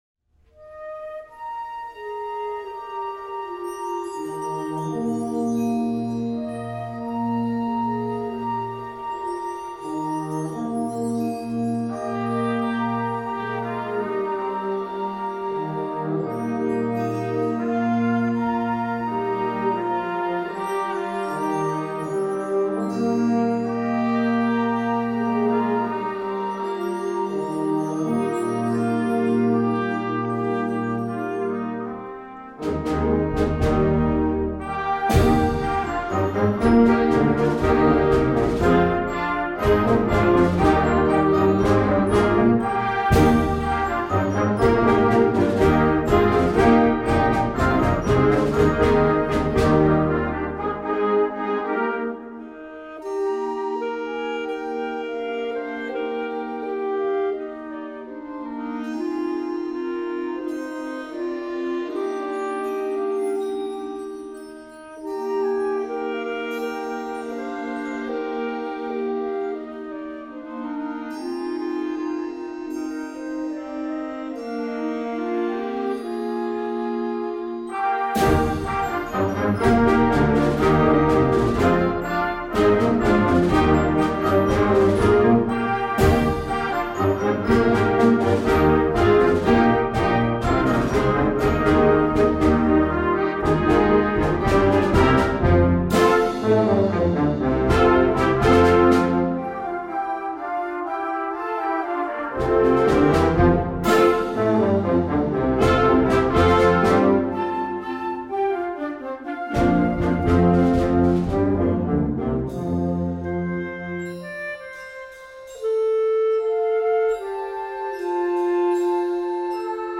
Song Type: Concert Band
Instrumentation: Band